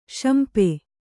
♪ śampe